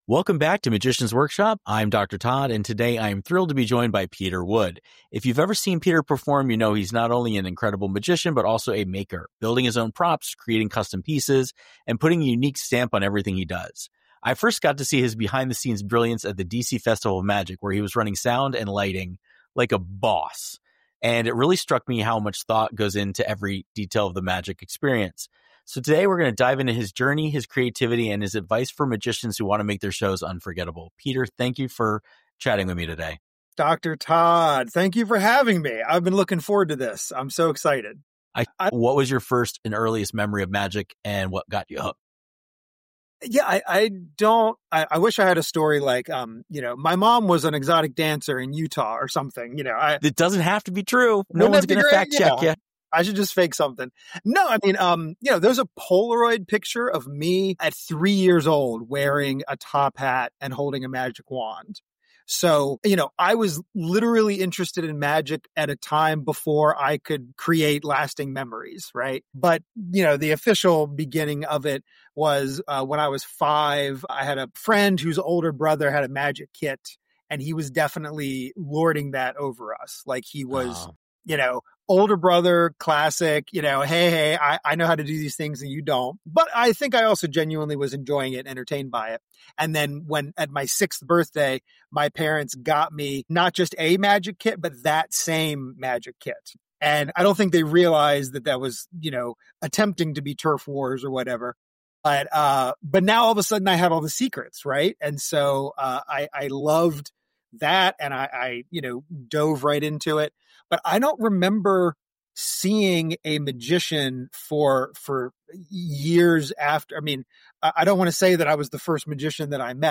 Each episode features in-depth interviews with renowned creators, performers, and innovators in magic, delving into their inspirations, creative processes, and the fascinating journey of turning ideas into reality.